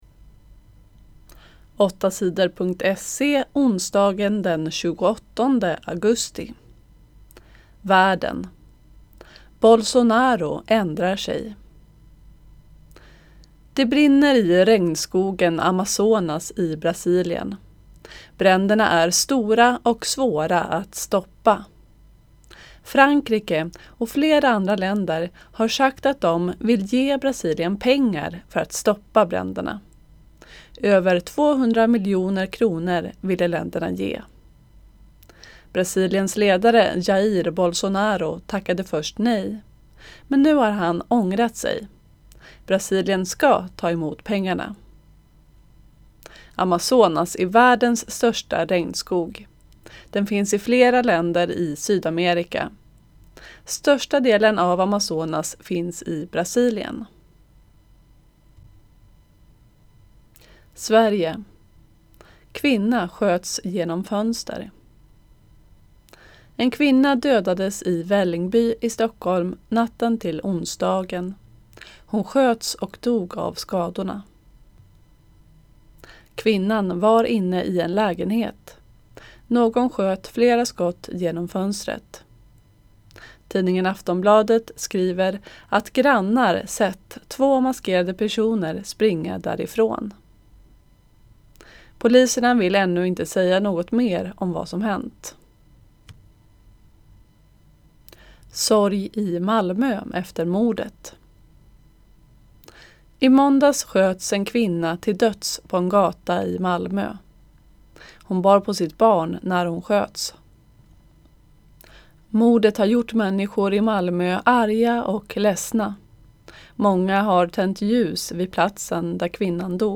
Inlästa nyheter den 28 augusti